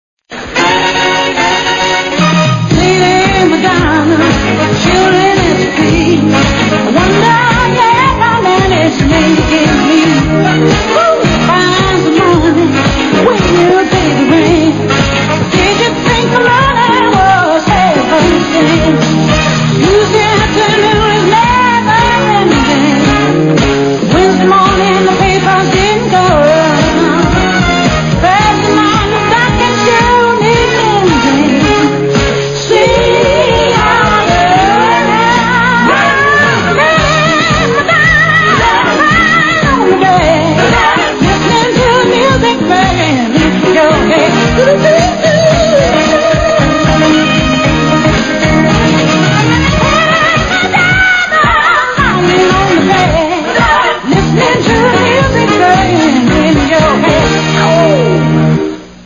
Theme